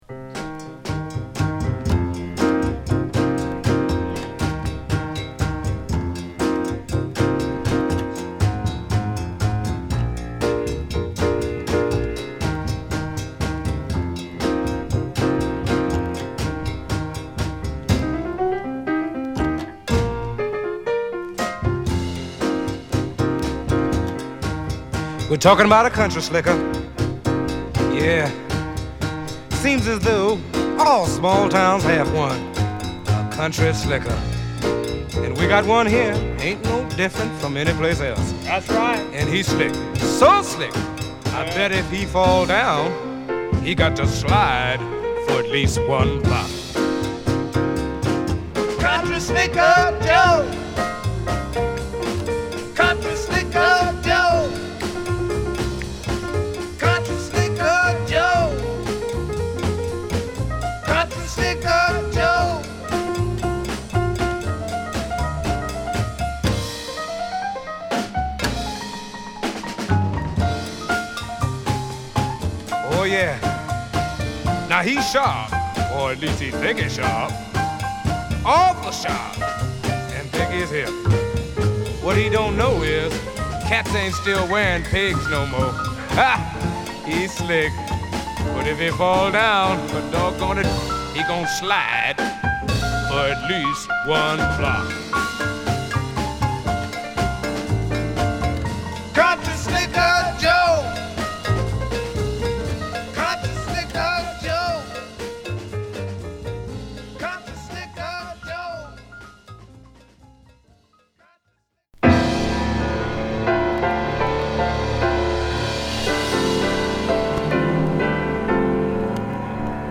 ソウル・ジャズ色強く
洗練されたピアノトリオを披露！